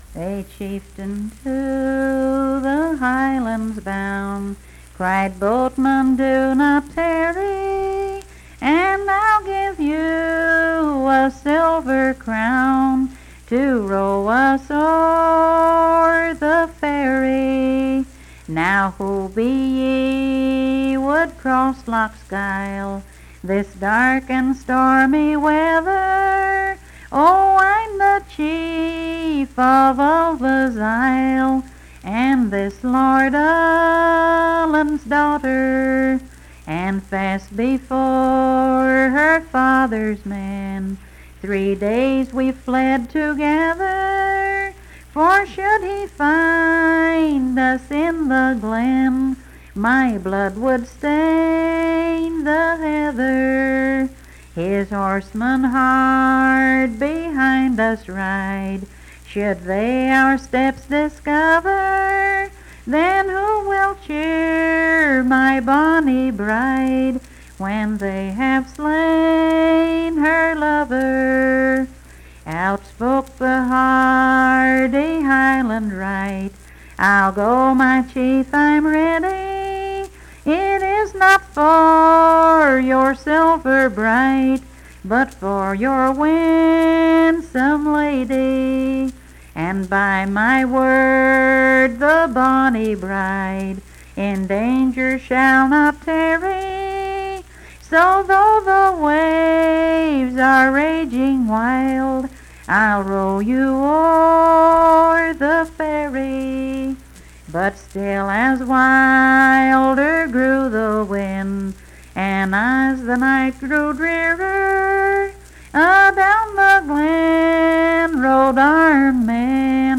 Unaccompanied vocal music performance
Voice (sung)